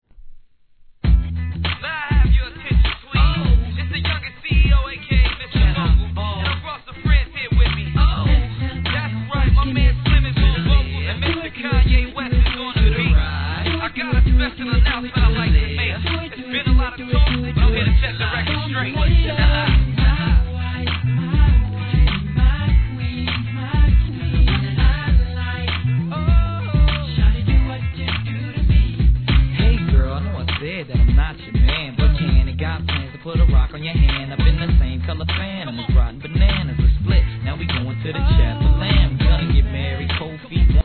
HIP HOP/R&B
ダンサブルでありながら落ち着きのあるトラック♪